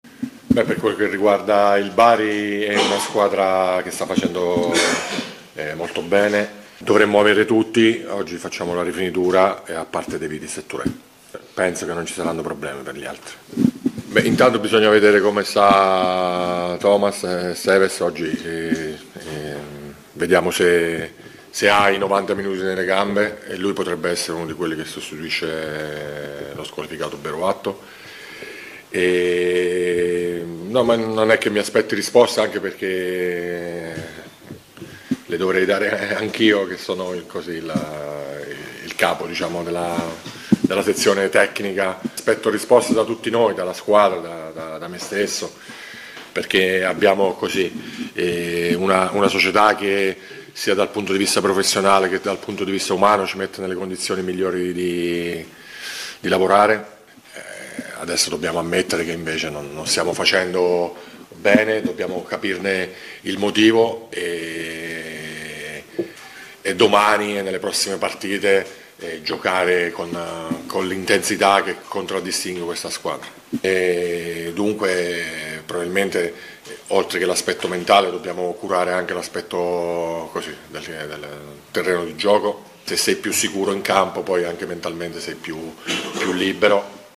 Un estratto delle dichiarazioni dell’allenatore neroazzurro.